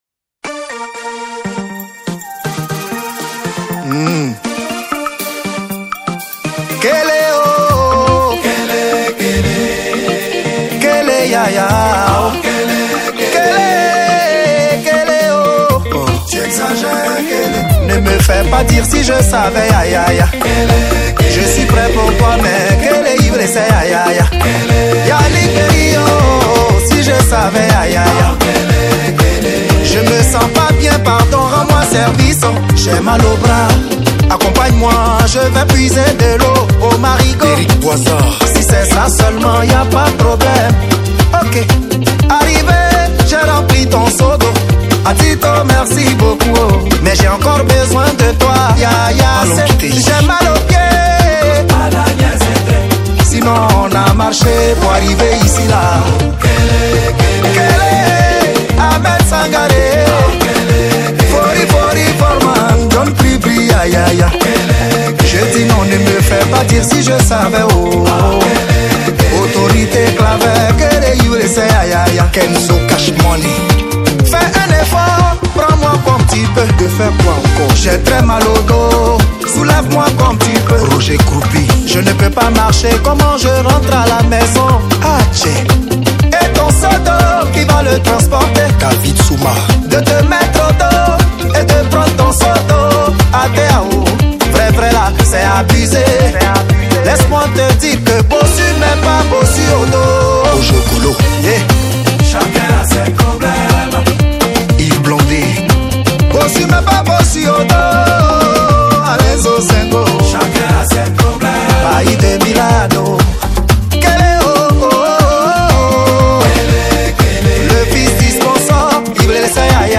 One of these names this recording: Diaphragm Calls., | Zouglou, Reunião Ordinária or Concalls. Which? | Zouglou